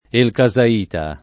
vai all'elenco alfabetico delle voci ingrandisci il carattere 100% rimpicciolisci il carattere stampa invia tramite posta elettronica codividi su Facebook elcasaita [ elka @ a & ta ] o elchesaita s. m. (stor.); pl.